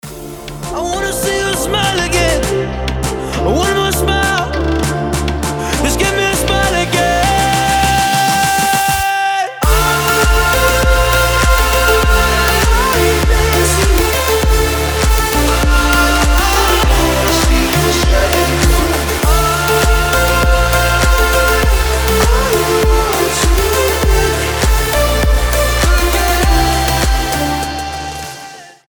• Качество: 320, Stereo
мужской голос
громкие
Electronic
EDM
future house
progressive house